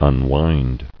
[un·wind]